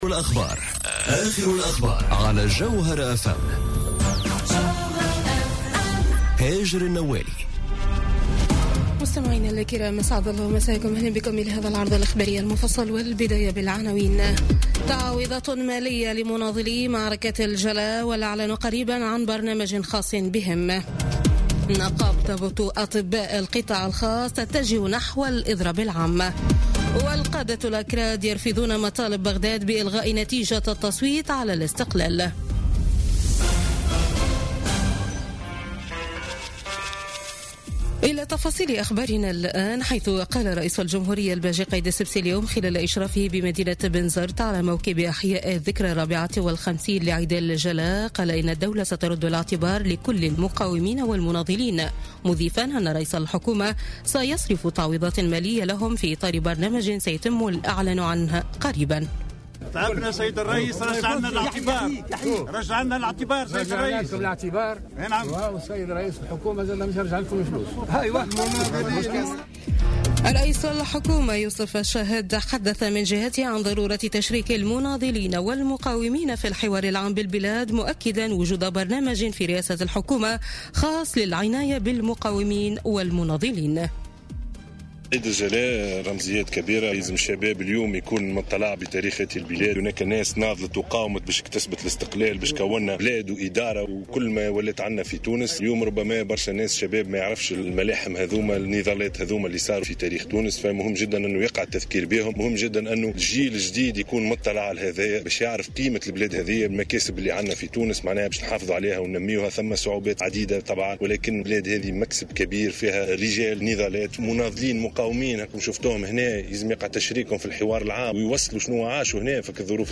نشرة أخبار السابعة مساء ليوم الأحد 15 أكتوبر 2017